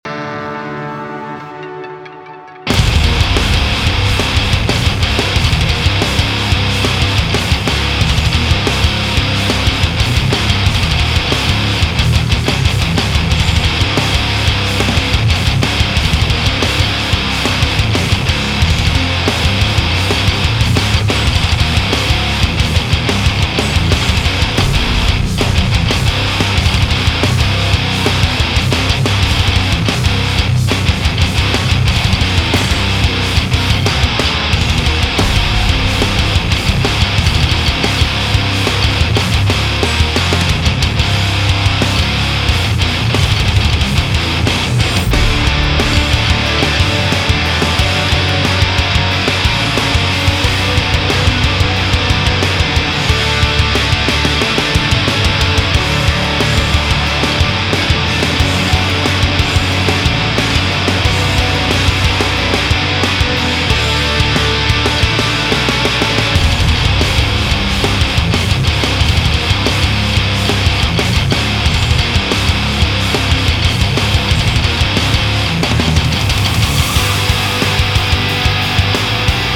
Rockt fett.